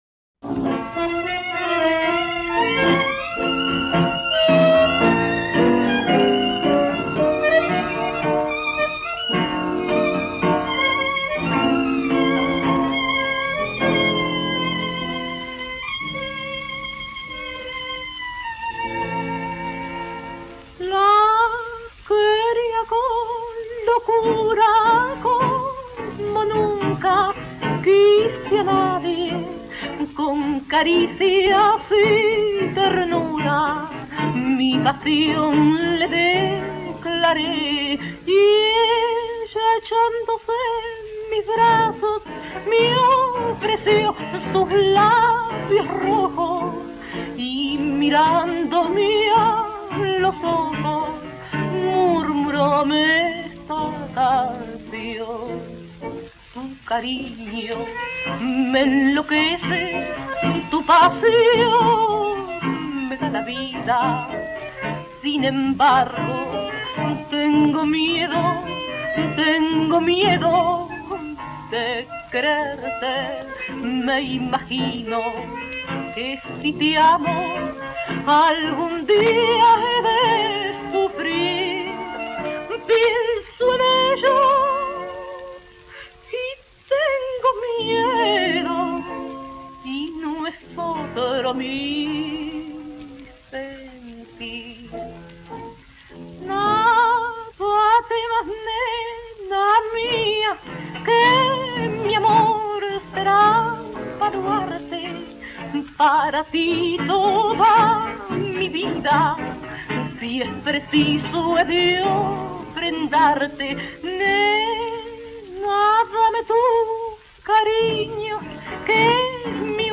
tanguera